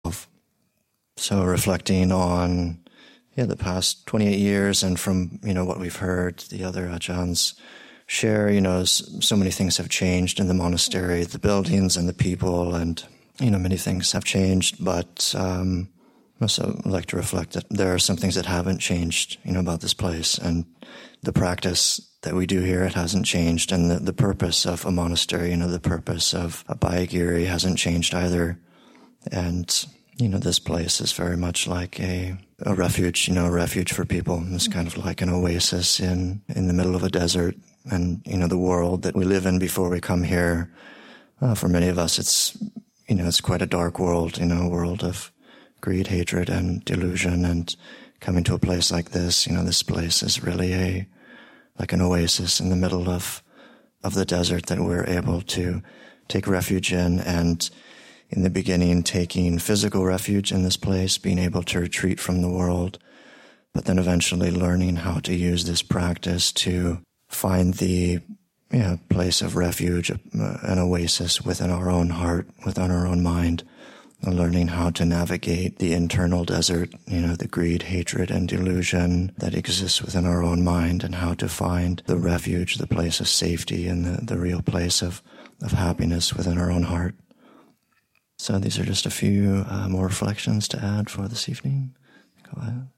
20. What has and hasn’t changed at Abhayagiri. Reflection